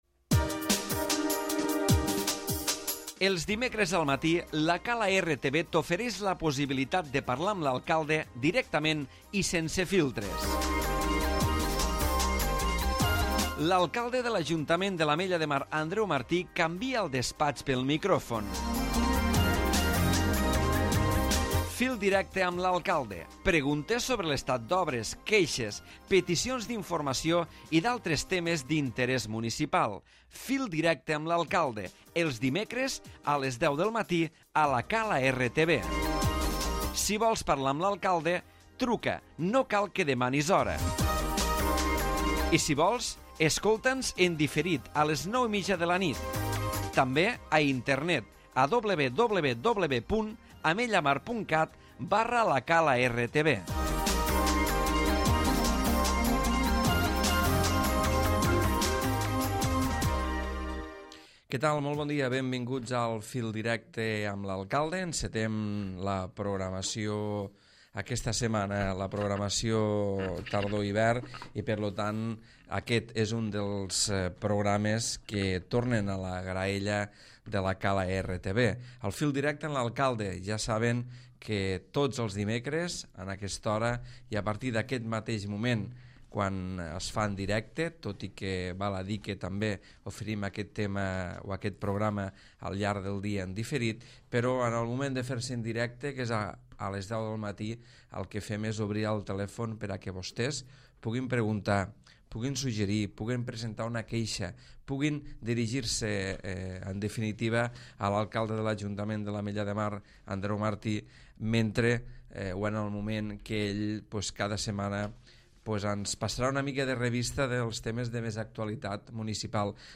Primer programa de la temporada. Programa on, a través del fil telefònic, els ciutadants poden parlar amb l'alcalde de l'Ametlla de Mar.